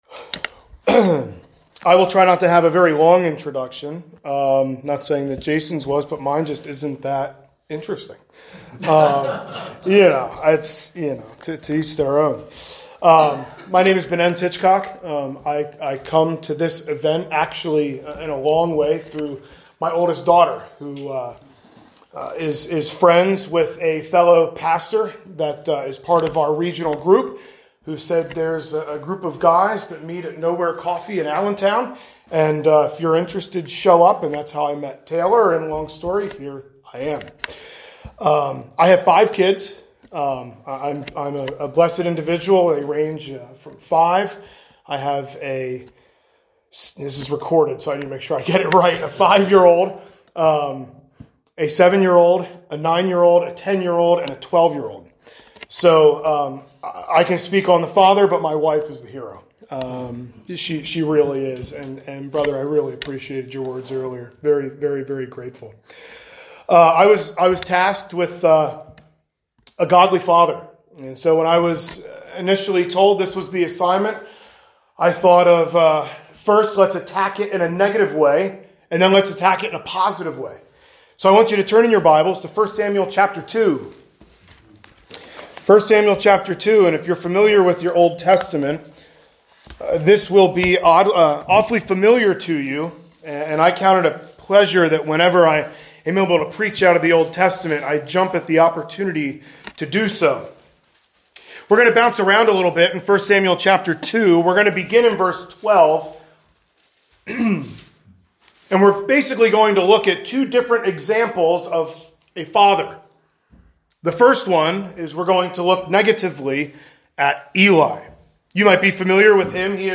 A sermon presented at the 2025 Men's Retreat at The Loft Ministries on the topic of A Godly Father.